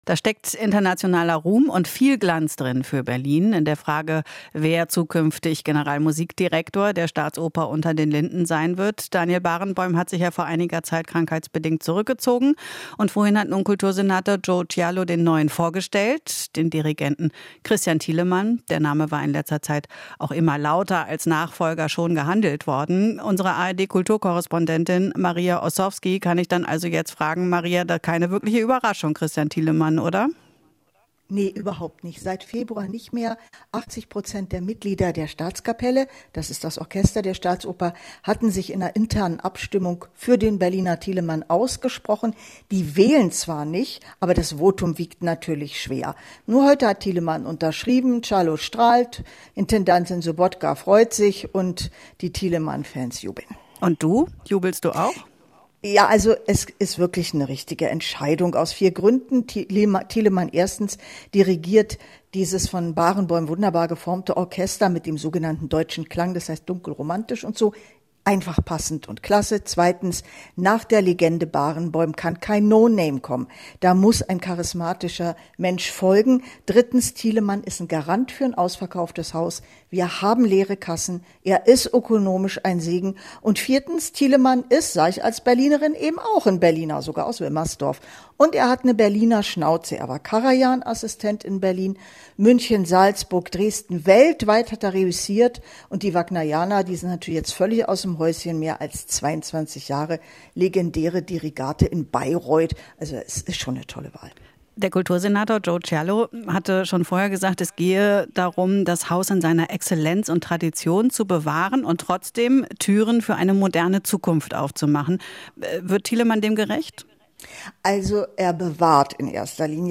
Interview - Thielemann wird neuer Generalmusikdirektor der Staatsoper